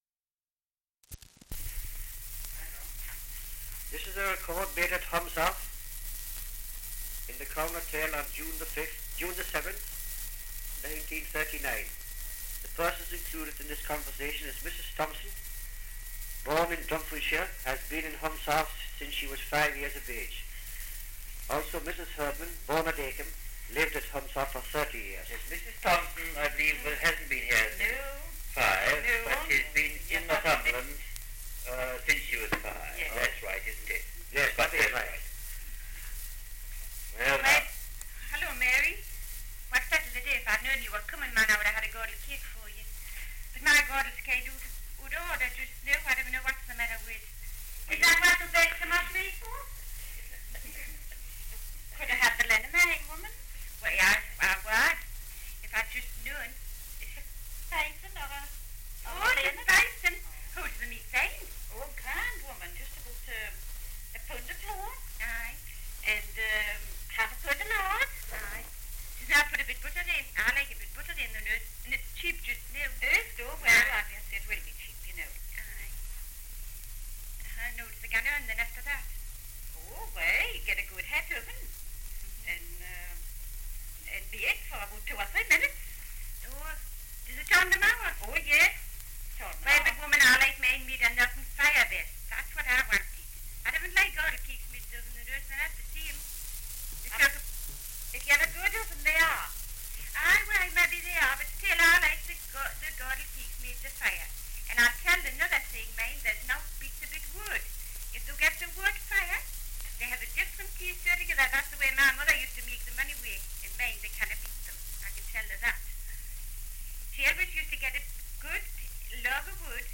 1 - Dialect recording in Humshaugh, Northumberland
78 r.p.m., cellulose nitrate on aluminium